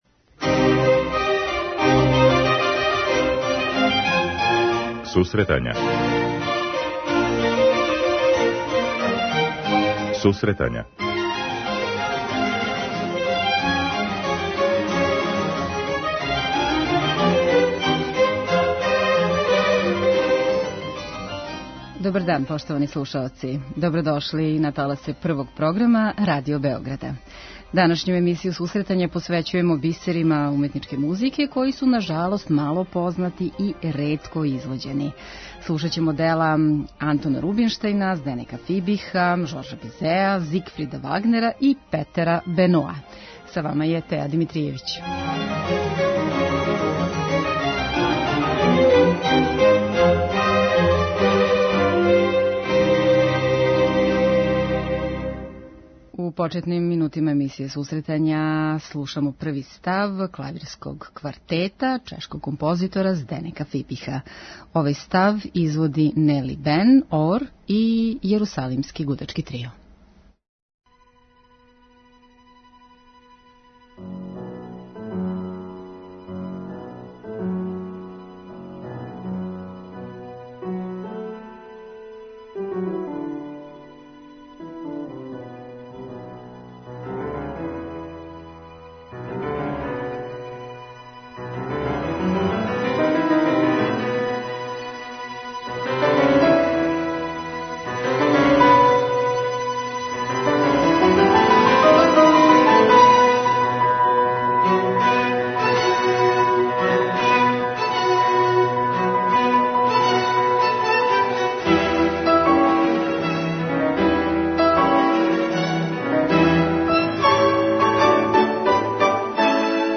Солистичка и оркестарска музикa XIX века
У данашњој емисији слушамо солистичка, камерна и оркестарска дела настала у последњим декадама XIX века, изникла из пера Бизеа, Фибиха, Рубинштајна, Беноа и Вагнера.